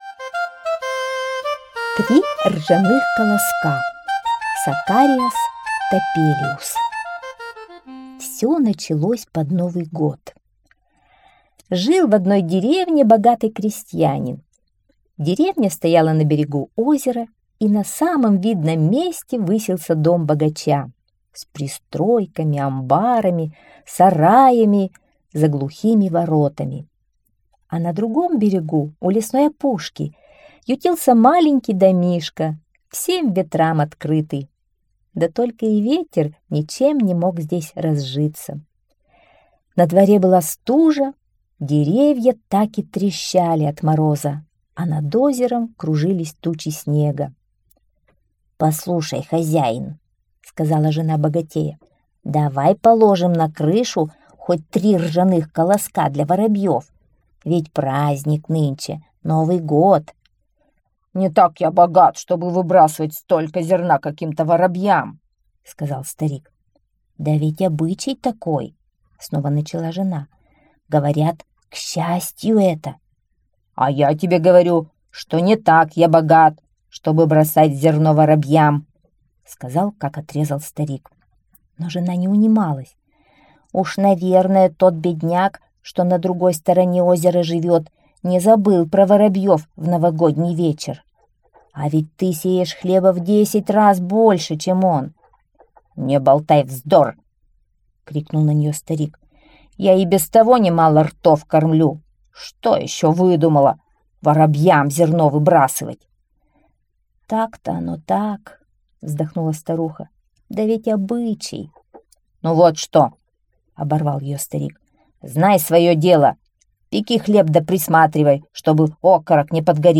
Три ржаных колоска - аудиосказка Топелиуса С. История про богатого крестьянина, который на Новый год пожалел для воробьев три ржаных колоска.